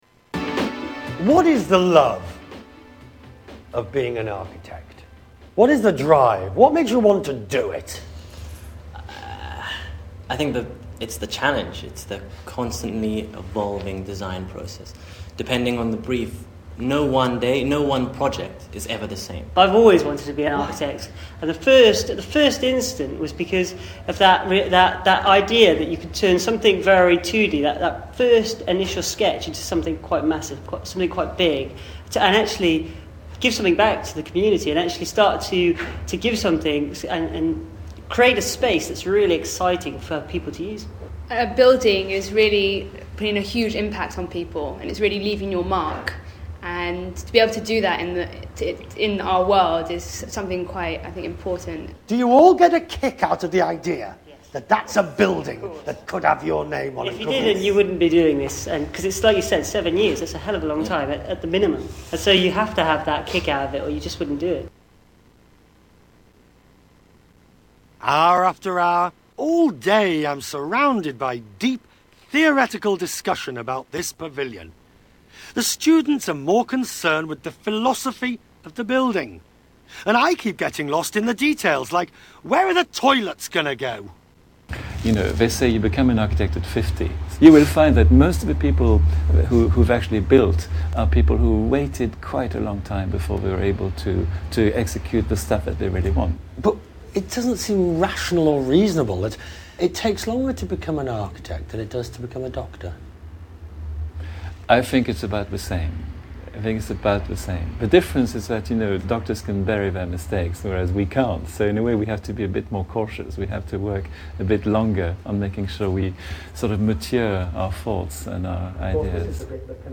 Interview with Architect students